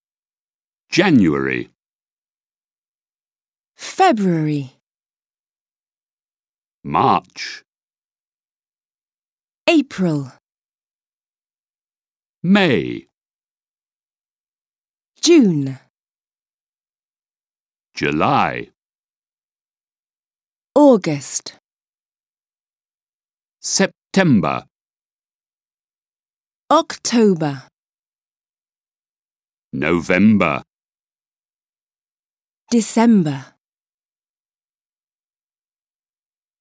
• Écoute et répétition : prononciation des mois
2. Listen and repeat.
Introduction_sequence-months-of-the-year.wav